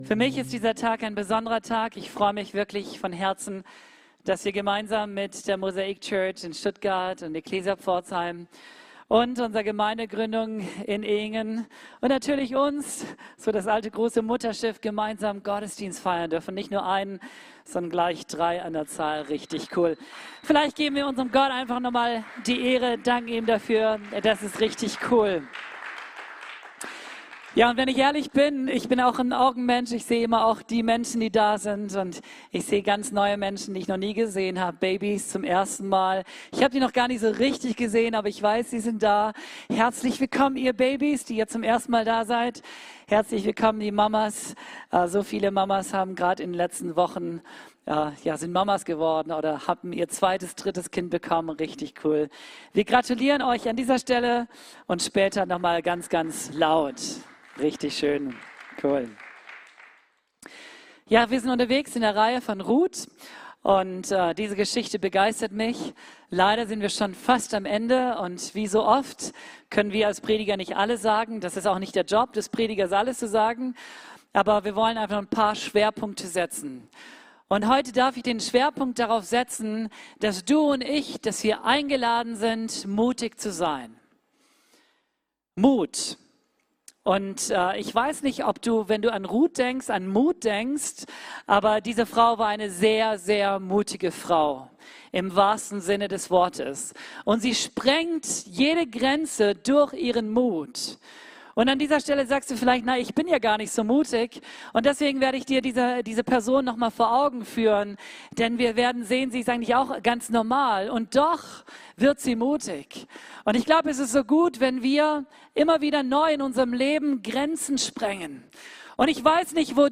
Februar 2021 AUDIO Online Gottesdienst The Story of RUTH